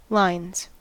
Ääntäminen
Ääntäminen US Tuntematon aksentti: IPA : /laɪnz/ Lyhenteet ja supistumat (laki) ll.